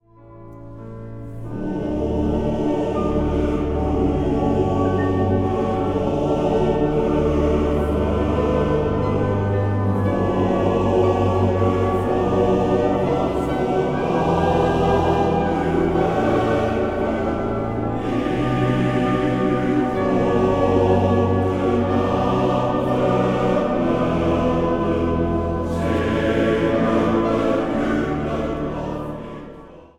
orgel
vleugel
hobo
bariton.
Zang | Mannenkoor